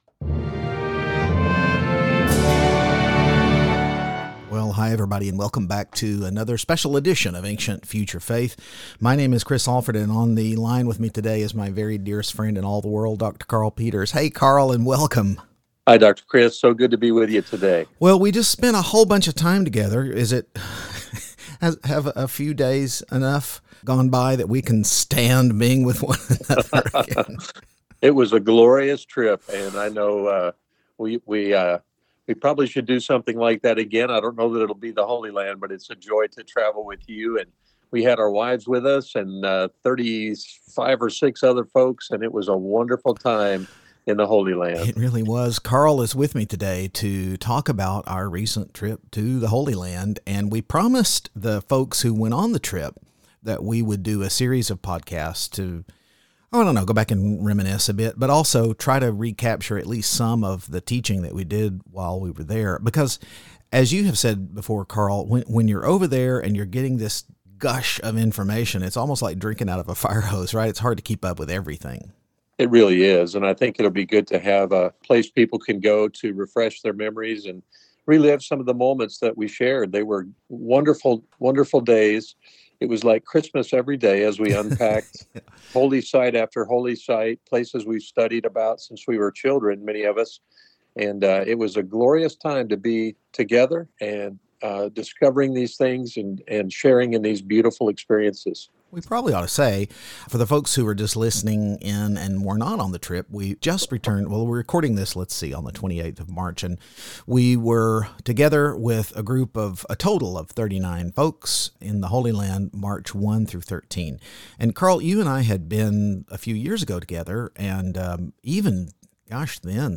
a series of conversations about their recent trip the Holy Land